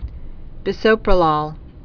(bĭs-ōprə-lôl, -lōl, bĭsō-prōlôl, -lōl)